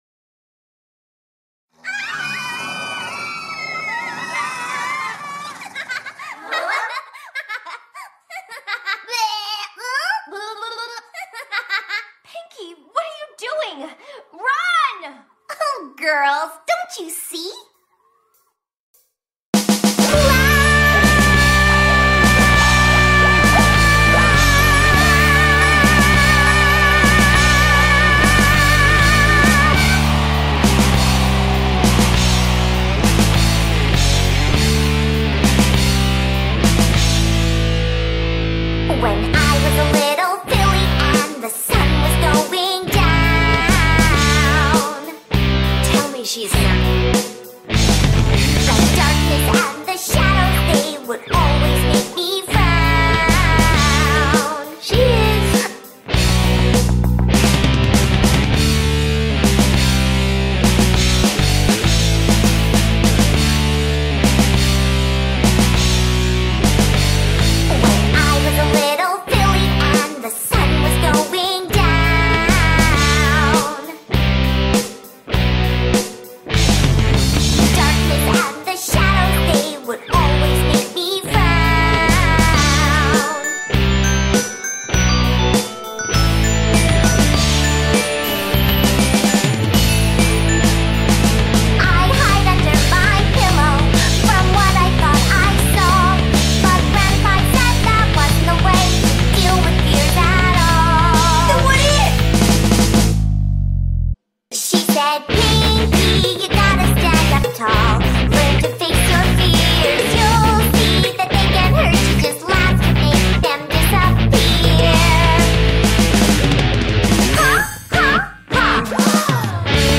More hard rock than just rock, hope you like it!!.